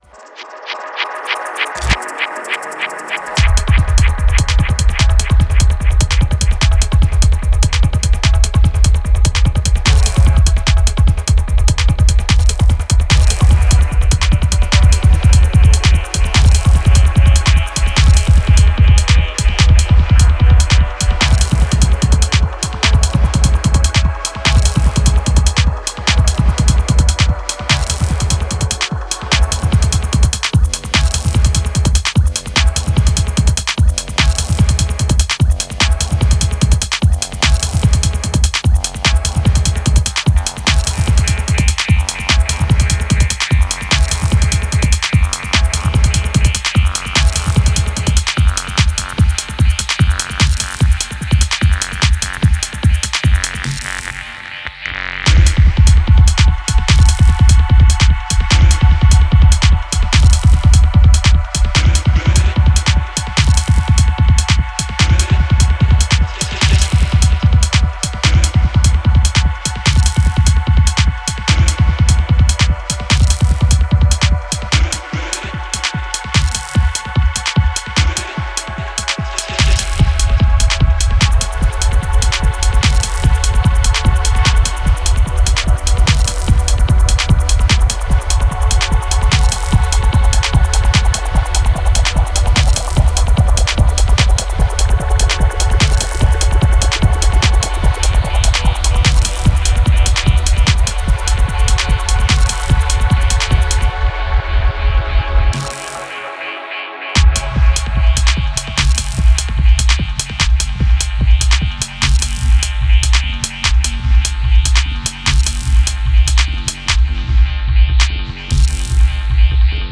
Genre Bass